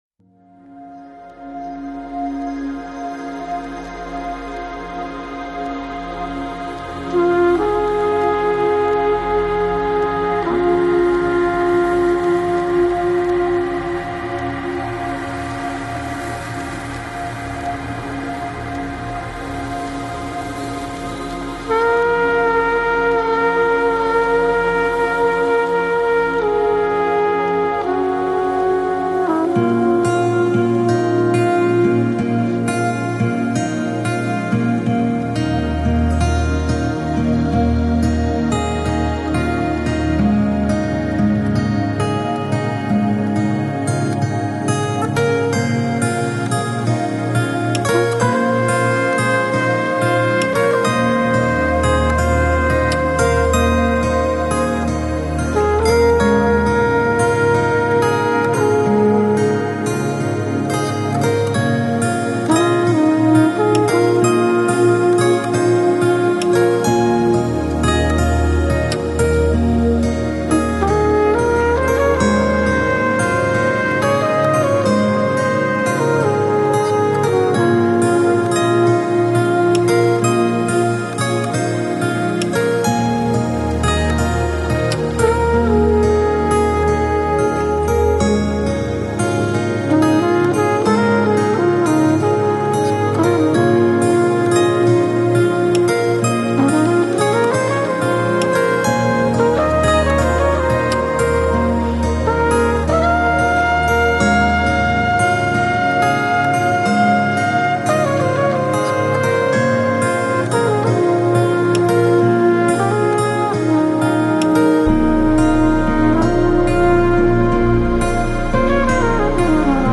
Жанр: Chillout | Lounge | Jazz